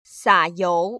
洒油[sǎ//yóu]